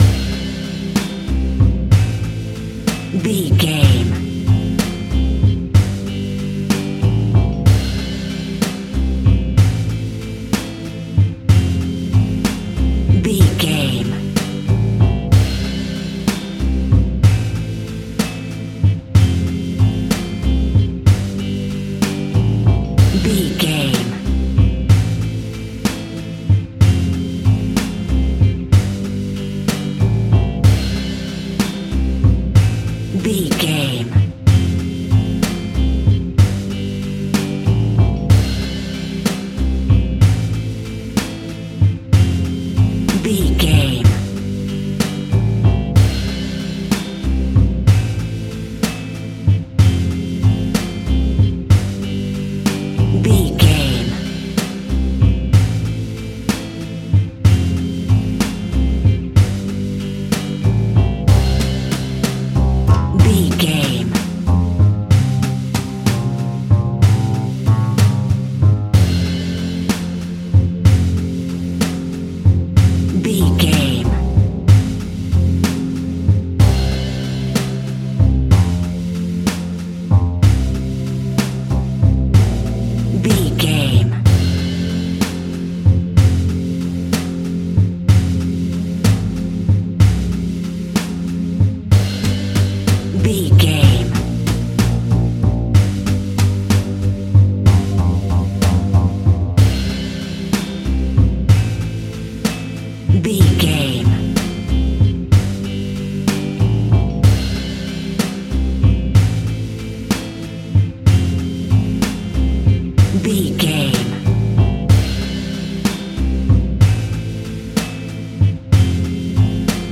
Uplifting
Ionian/Major
blues
country guitar
acoustic guitar
bass guitar
drums
hammond organ